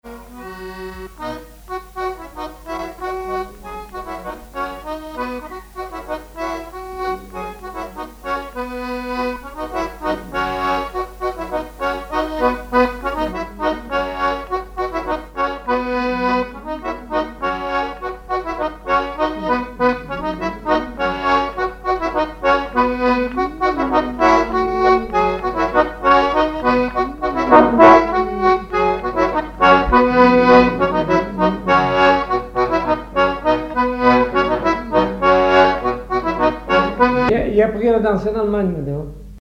Saint-Hilaire-de-Riez
danse : bourree
Répertoire sur accordéon diatonique
Pièce musicale inédite